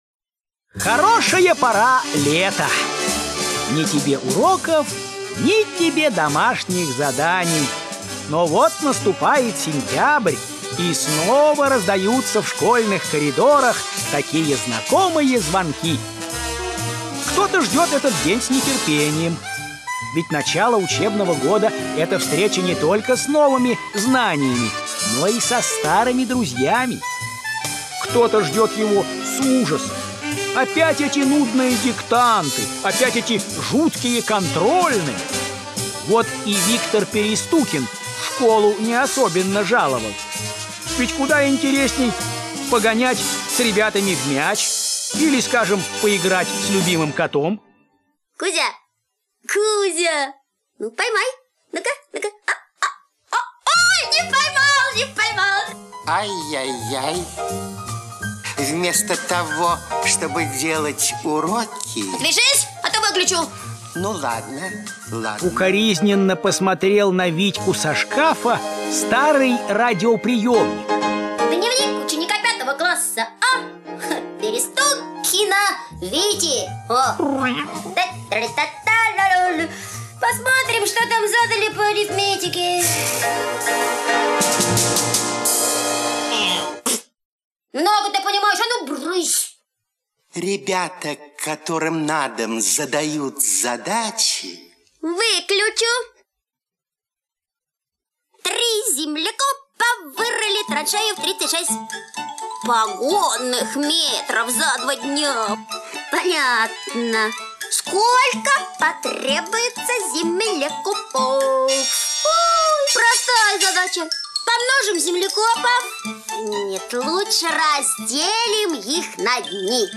В стране невыученных уроков - аудиосказка Гераскиной - слушать онлайн